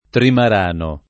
[ trimar # no ]